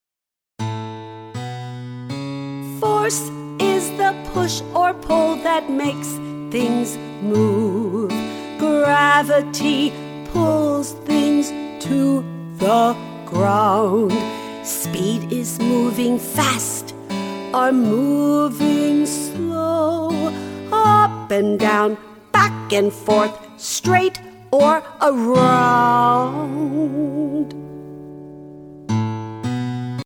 Learn science with these fun and upbeat songs!